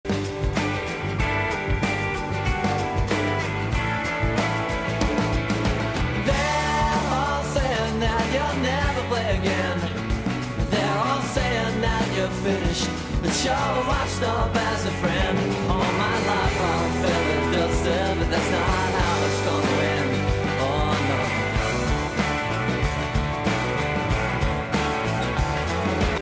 Australian rock band